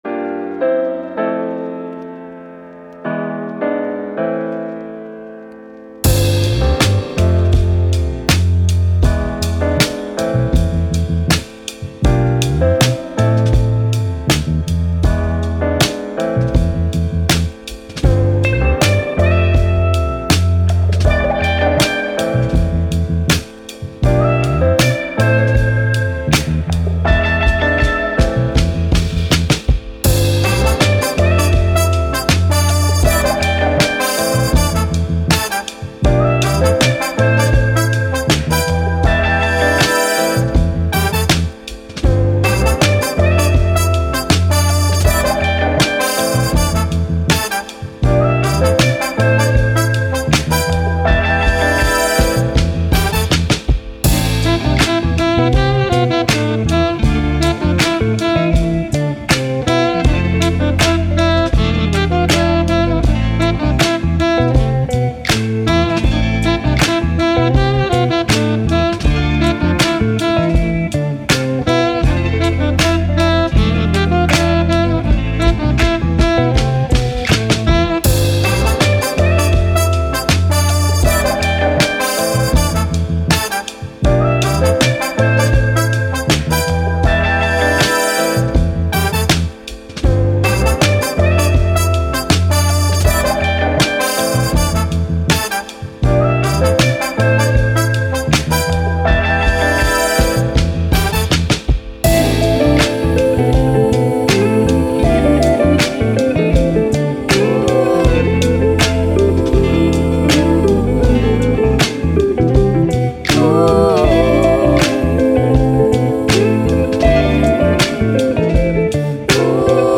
Soul, Hip Hop, Vintage, Vibe, Chilled, Happy